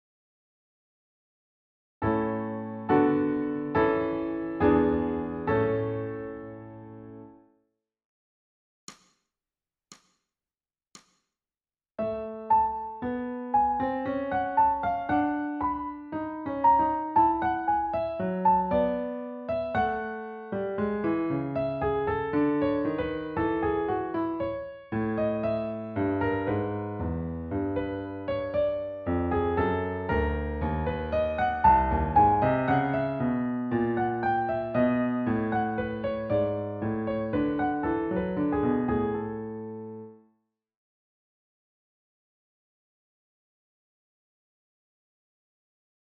ソルフェージュ 聴音: 2-2-14